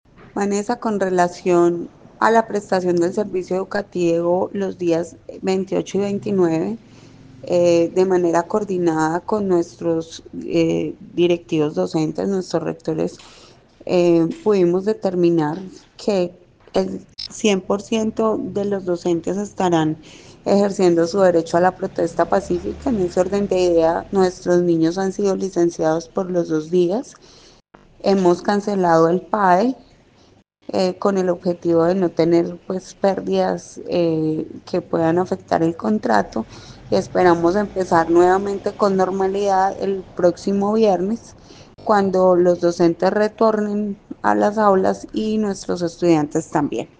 Secretaria de Educación de Armenia